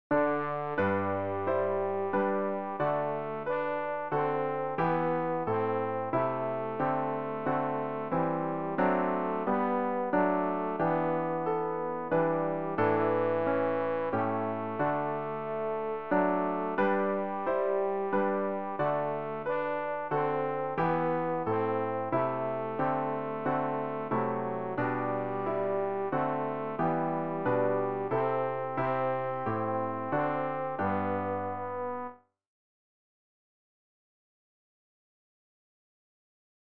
rg-605-der-tag-mein-gott-tenor.mp3